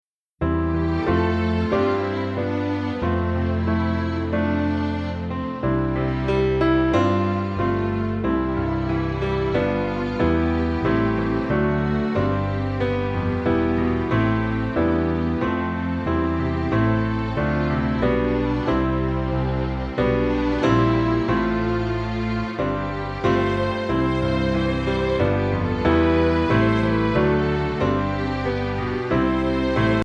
Voicing: Flute w/ Audio